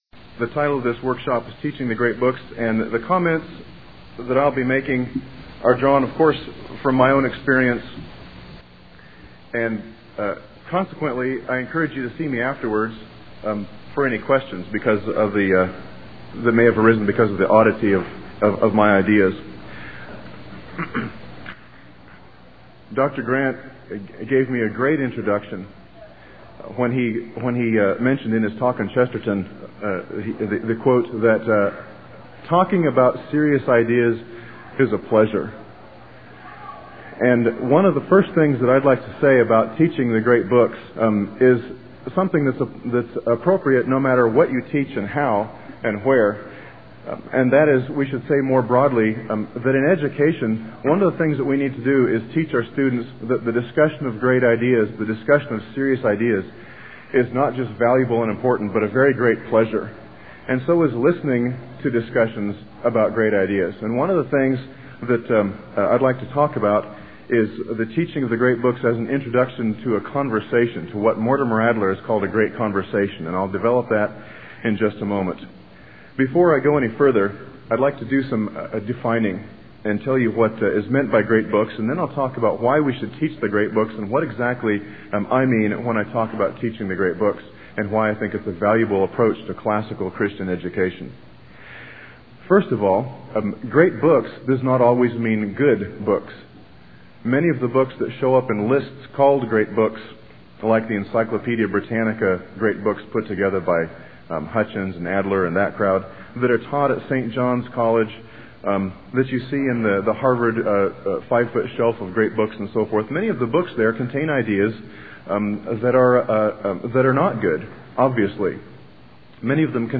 2002 Workshop Talk | 1:04:17 | All Grade Levels, Literature
The Association of Classical & Christian Schools presents Repairing the Ruins, the ACCS annual conference, copyright ACCS.